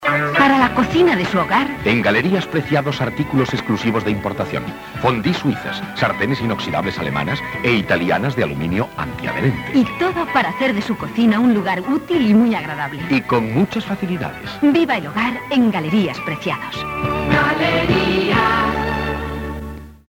Descripció Publicitat dels magatzems Galerías Preciados Gènere radiofònic Publicitat